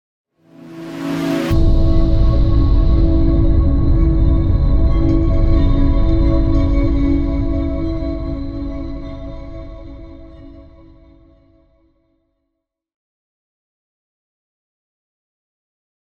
moonbeam-intro_no_pulse-v1-001.ogg